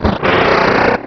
Cri de Sharpedo dans Pokémon Rubis et Saphir.